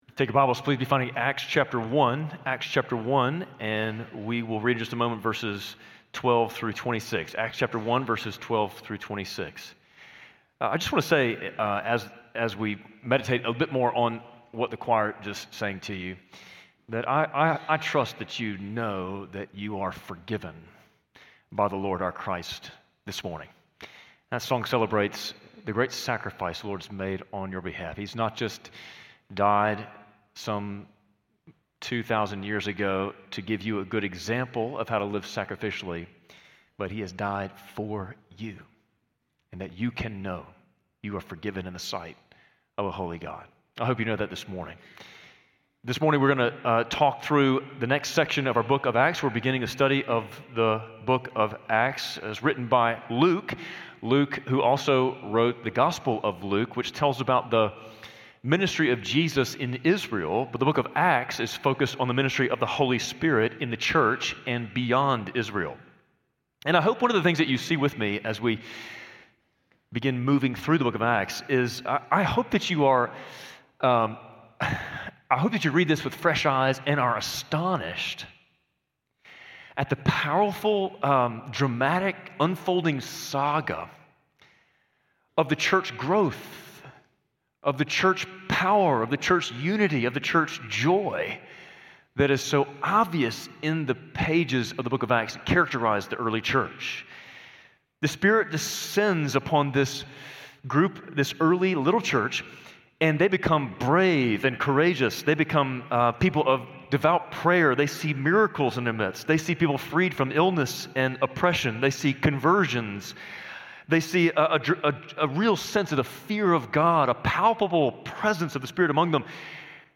Spring Hill Baptist Sunday Sermons (Audio) / Hoist the Sails